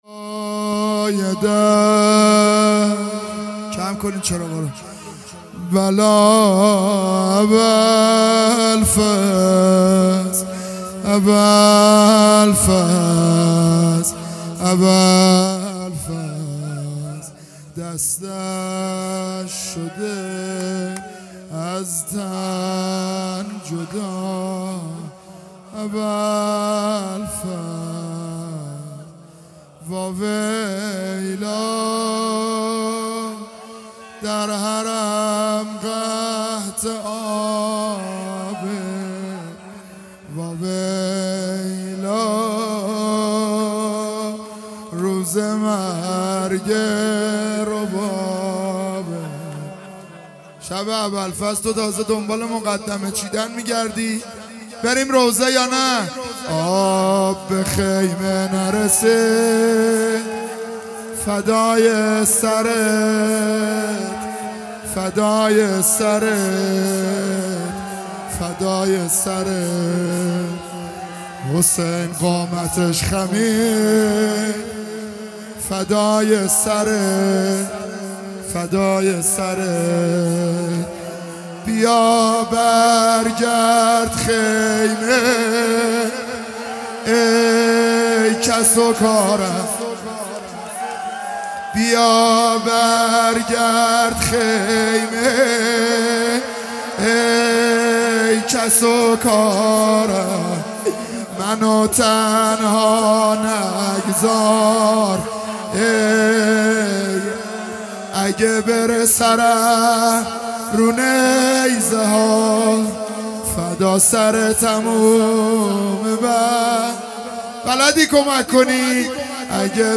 محرم 98 شب تاسوعا - روضه